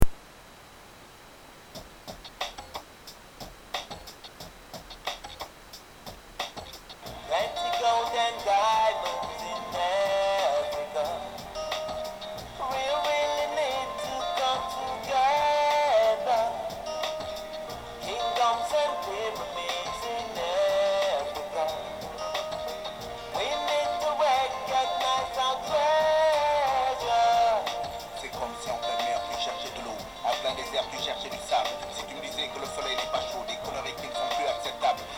Chant / choeurs